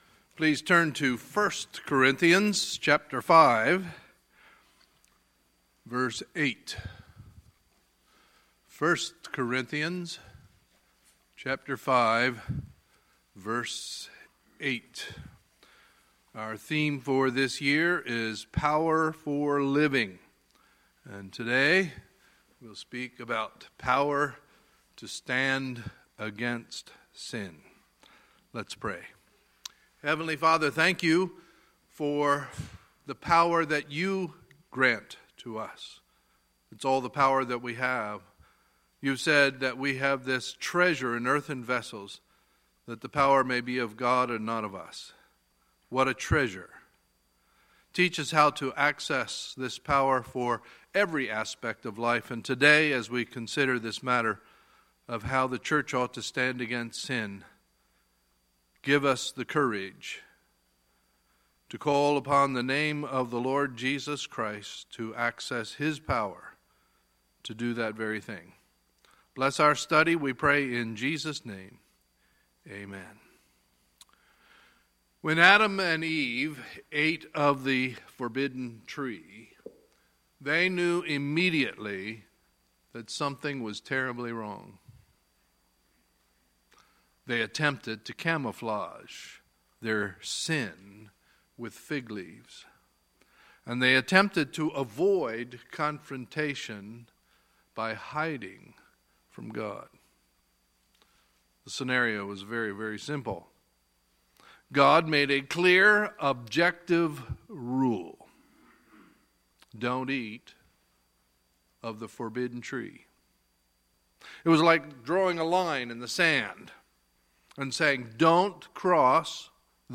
Sunday, February 26, 2017 – Sunday Morning Service